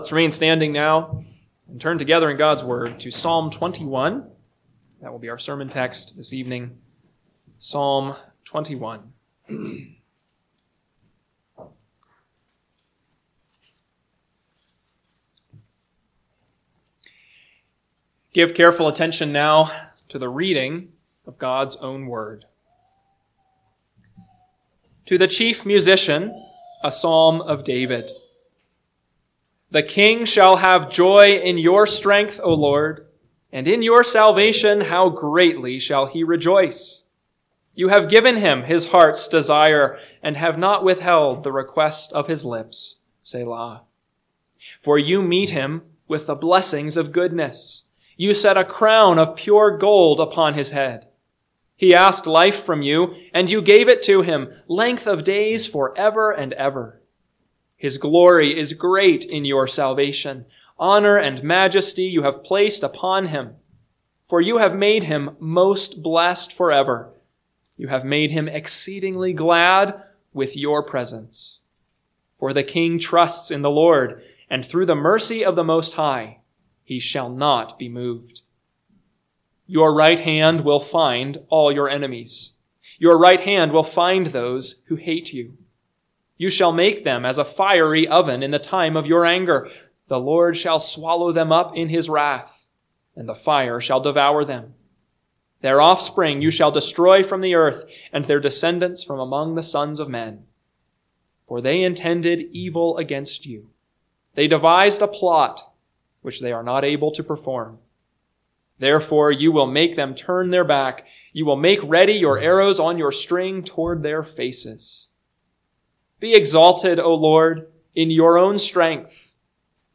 PM Sermon – 1/26/2025 – Psalm 21 – Northwoods Sermons